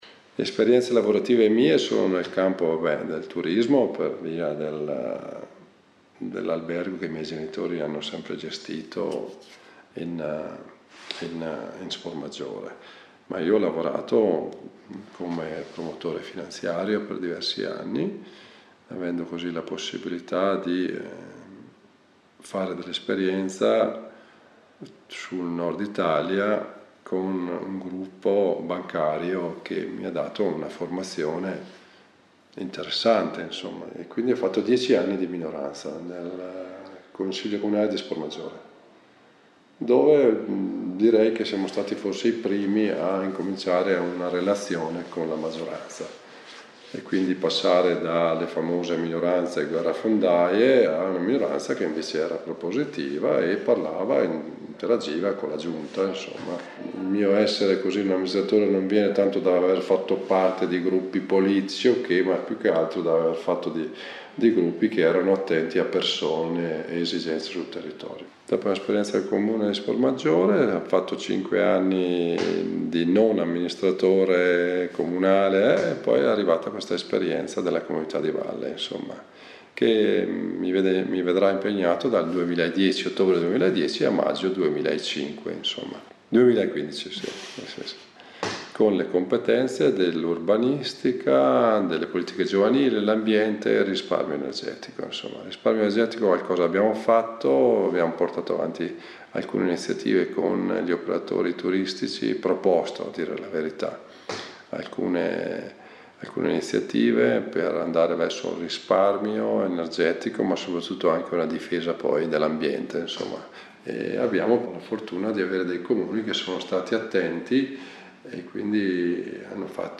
Presentazione audio dell'assessore Werner Decarli